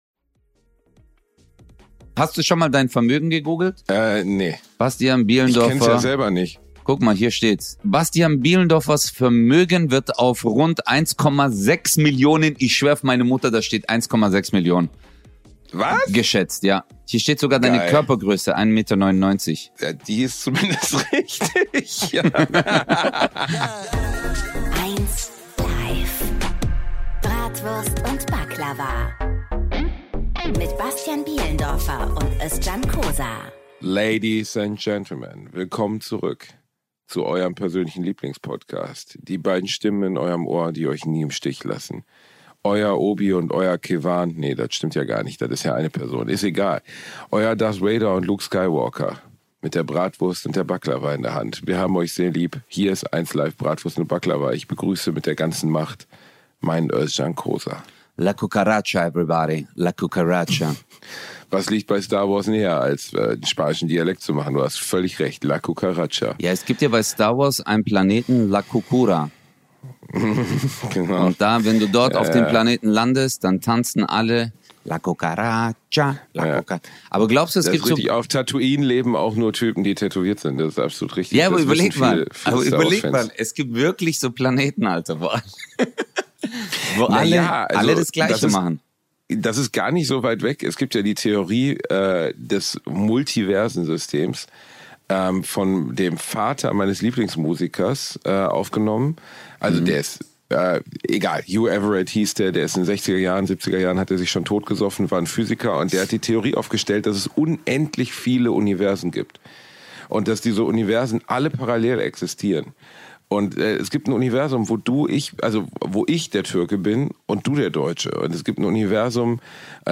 Wir verlangen außerdem den Preis für die schlechtesten Akzente und Dialekte der Welt für Basti.